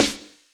just SNARES 2
snareldk52.wav